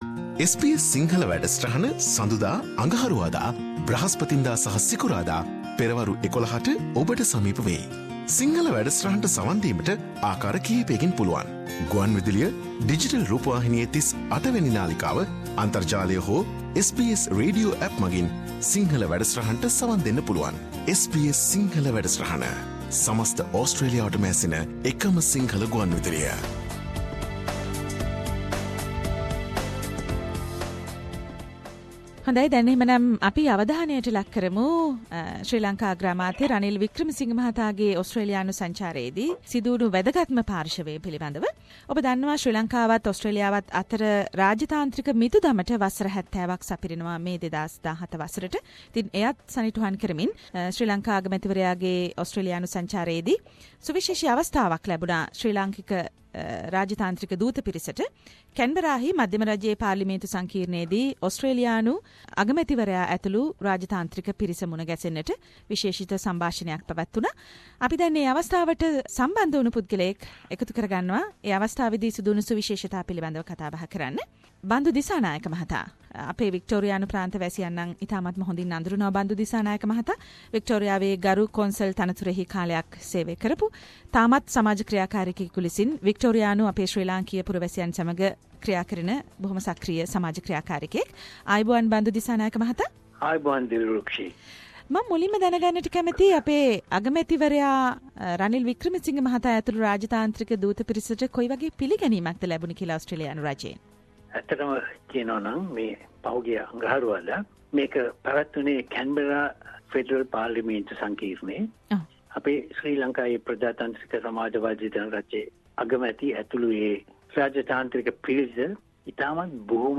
Sri Lankan Prime Minister’s visit to Australia: A Special radio feature on SBS Sinhalese program